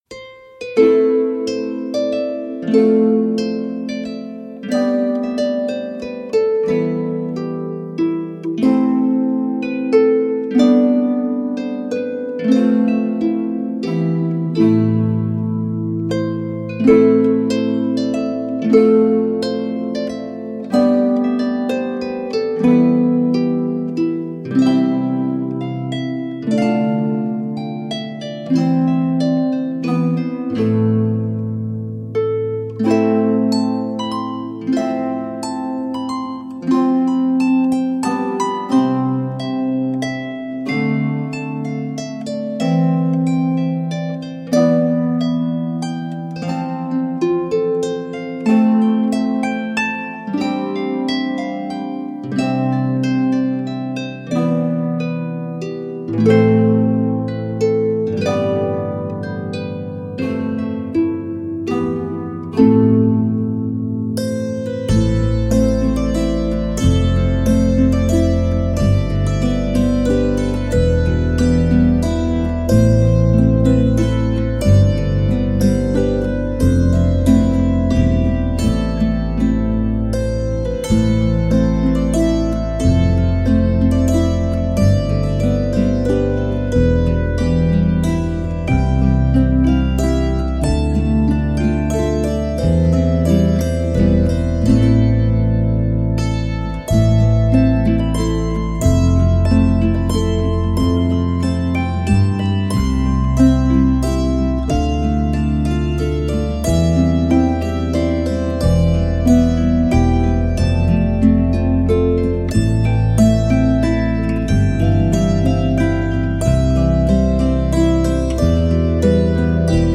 Meditative Klänge aus Irland